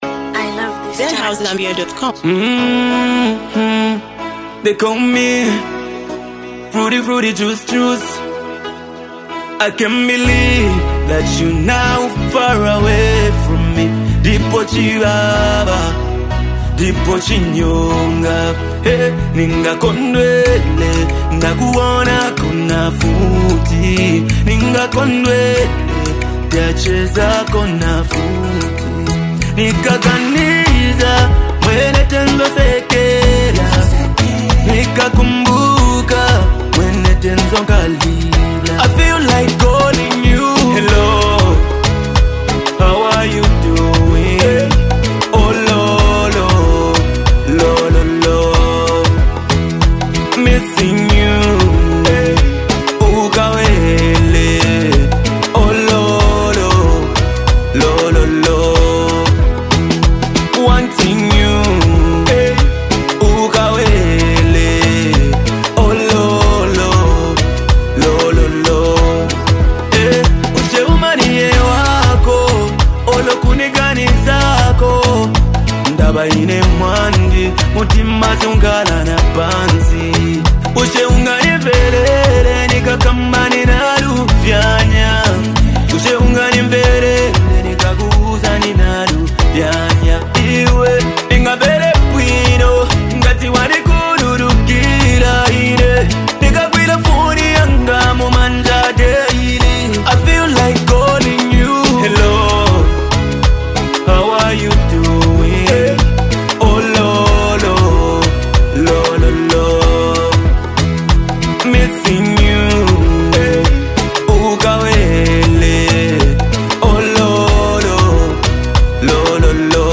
” a heartfelt tune where he pours out his emotions